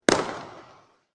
shoot.mp3